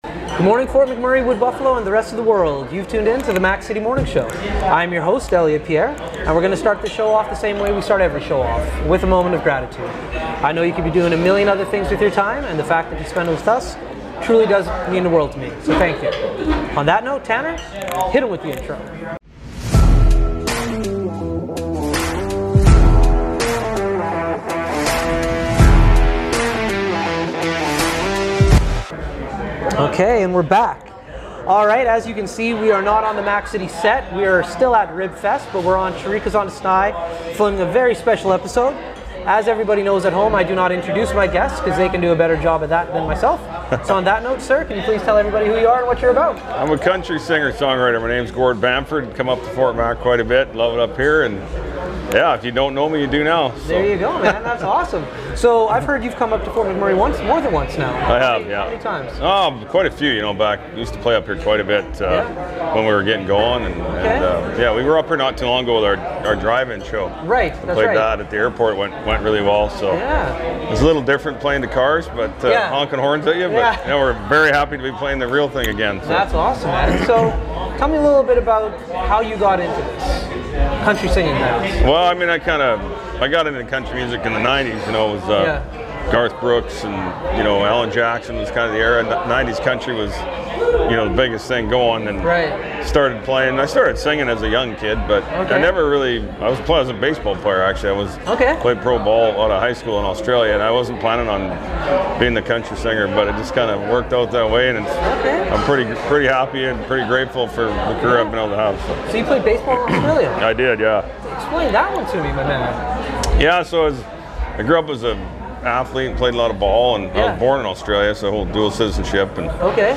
Gord Bamford, country singer/songwriter is on the show today! Gord was in town to perform at Rib Fest this summer!